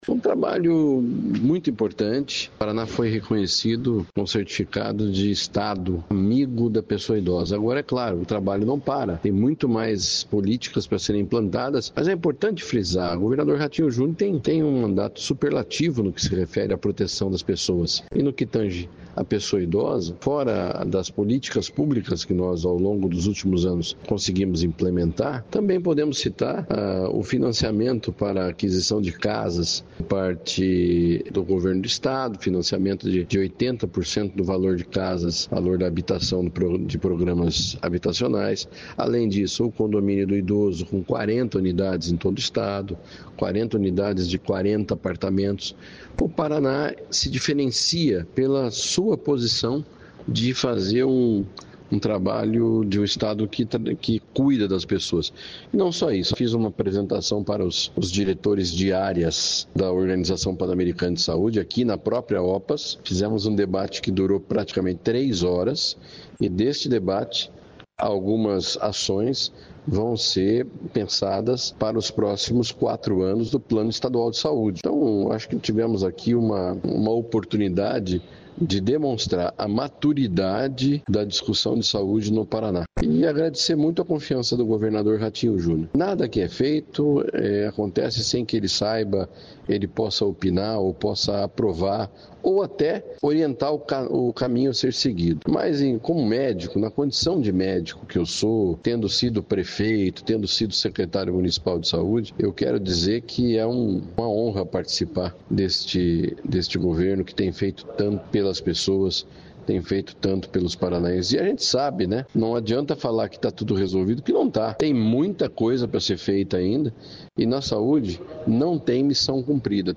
Sonora do secretário de Estado da Saúde, Beto Preto, sobre sede da Opas nos EUA